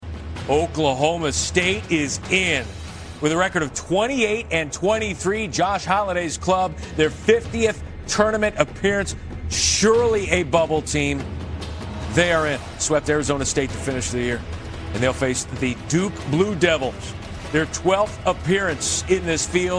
Here is how the Poke’s selection sounded on ESPN2.